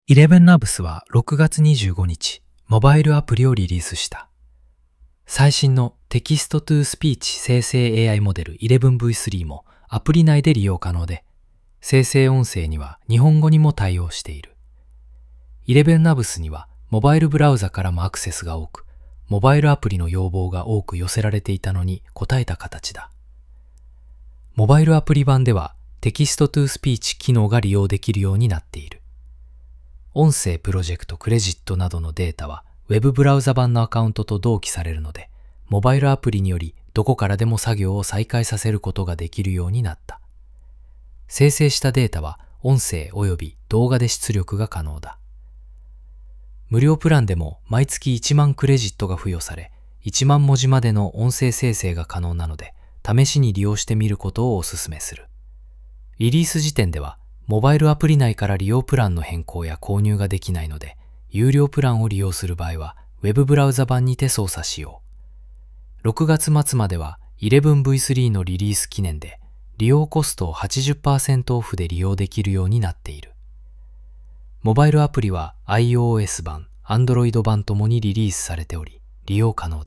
by ElevenLabs